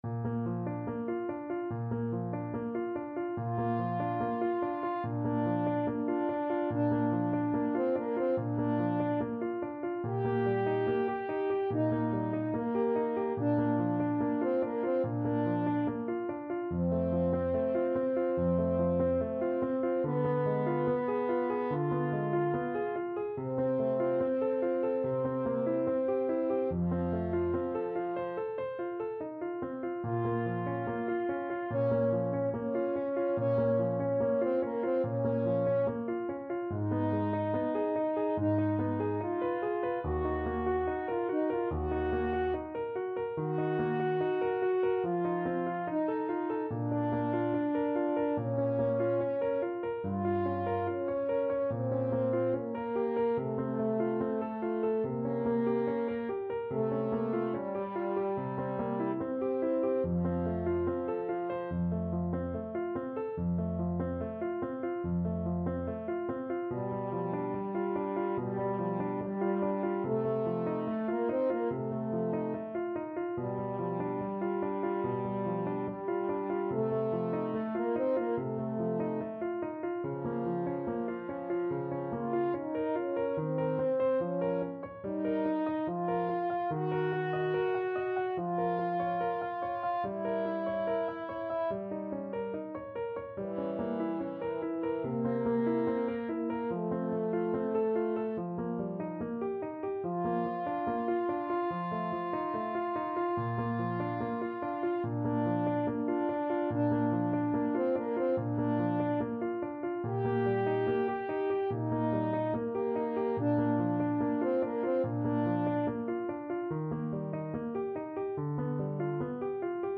Andante =72
Classical (View more Classical French Horn Duet Music)